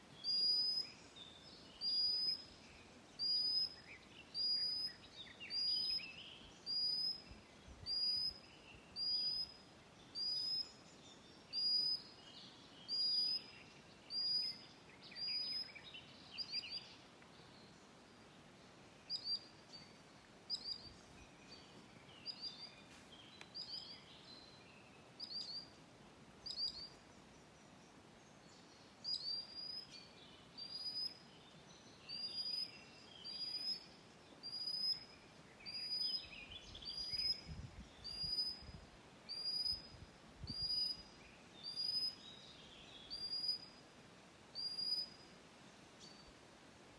Birds -> Waders ->
Common Sandpiper, Actitis hypoleucos